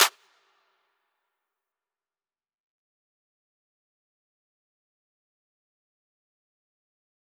DMV3_Clap 19.wav